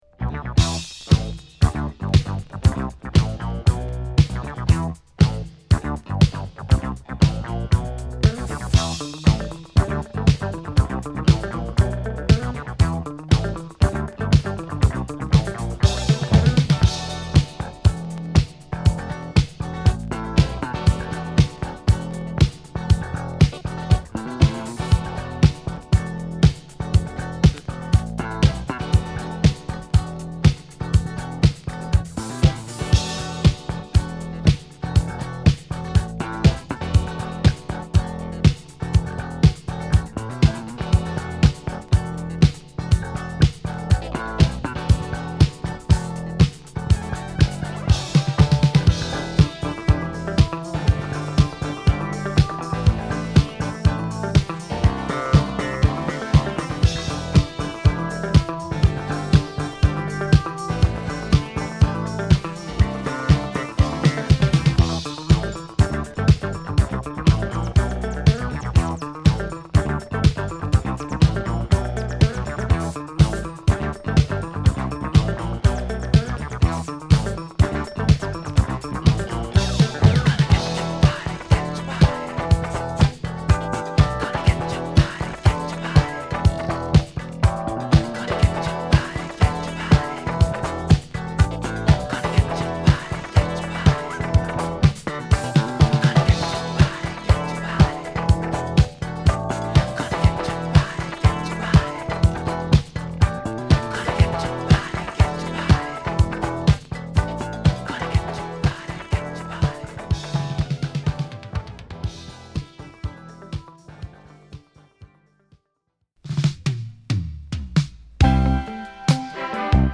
メンフィス出身のファンクバンド